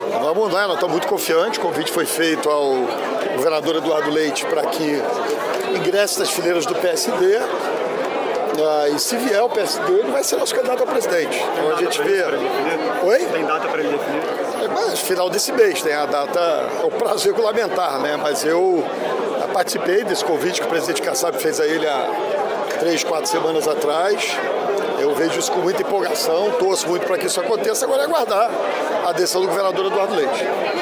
Durante a cerimônia de filiação de novos integrantes do PSD, em um hotel, no Centro do Rio, neste domingo, o prefeito Eduardo Paes declarou que o governador Eduardo Leite, do Rio Grande do Sul, foi convidado para ser o candidato do partido à presidência da República.